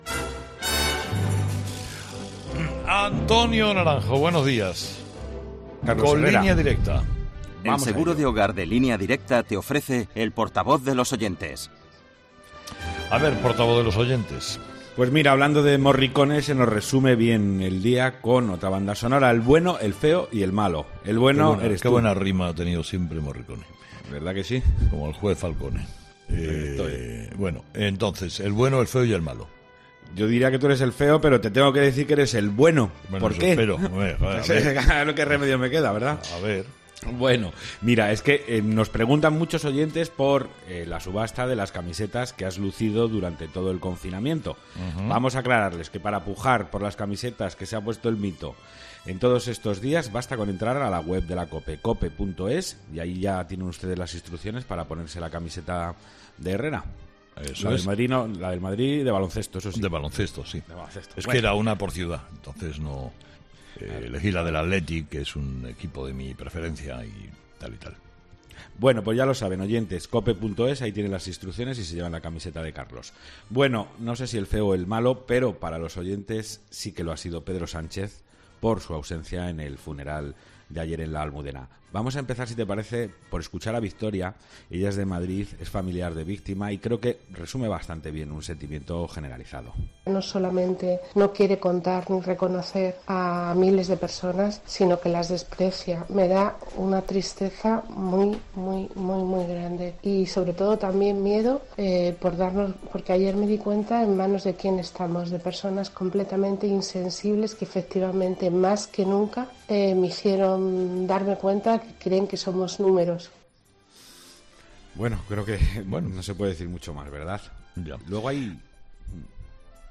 Avalancha de mensajes en el contestador de 'Herrera en COPE' con los temas del día.